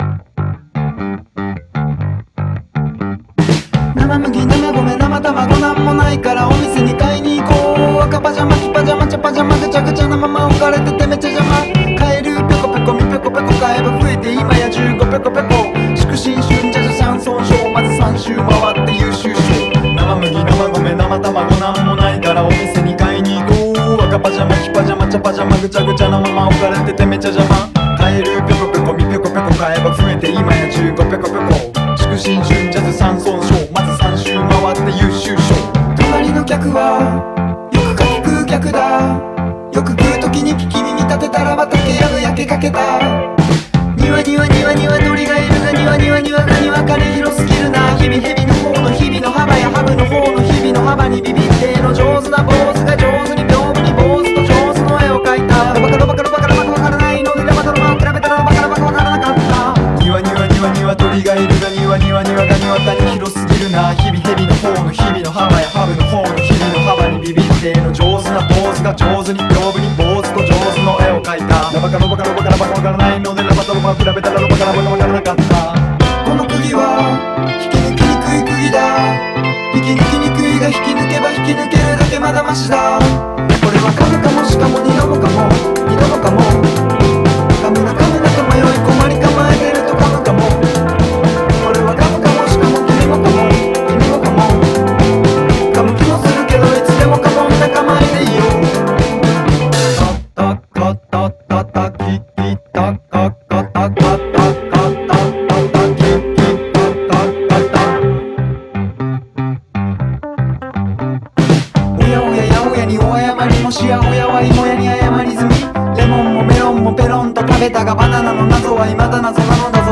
BPM120
Audio QualityPerfect (High Quality)
-Audio updated to have less clipping